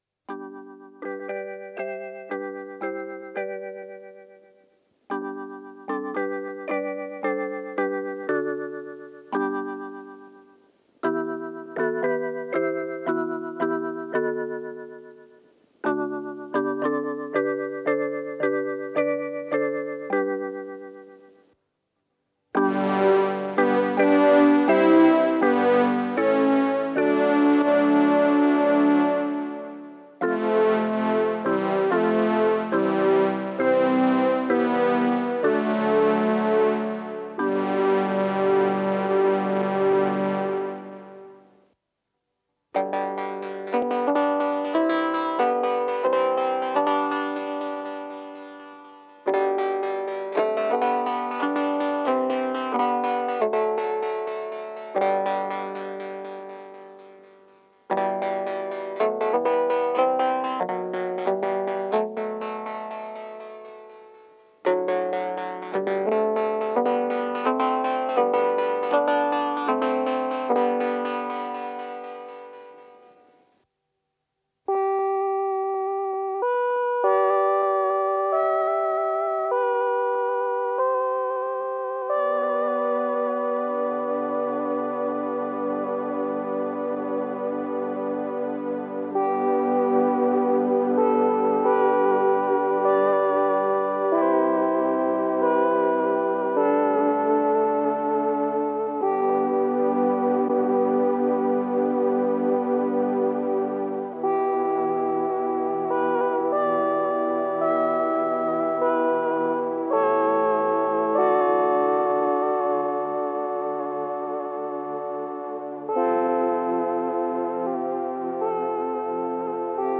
Sintonia del període de proves
FM